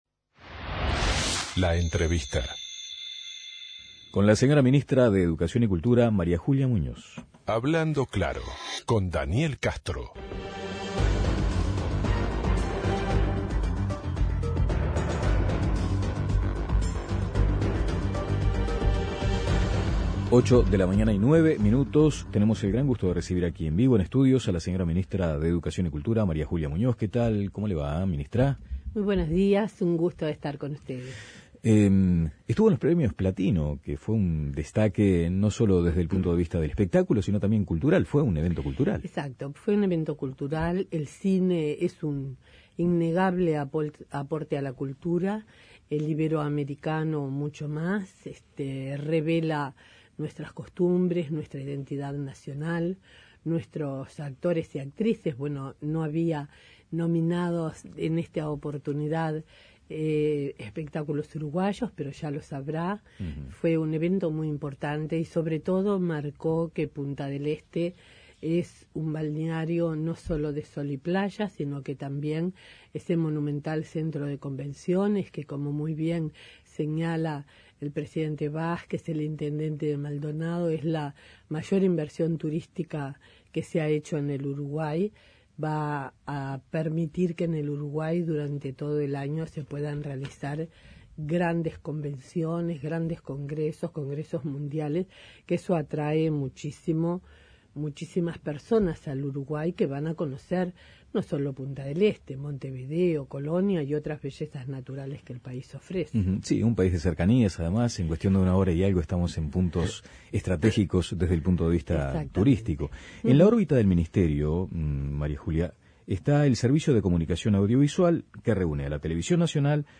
Muñoz dijo en La Mañana de El Espectador que "ese fue un muy mal día".